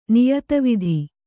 ♪ niyata vidhi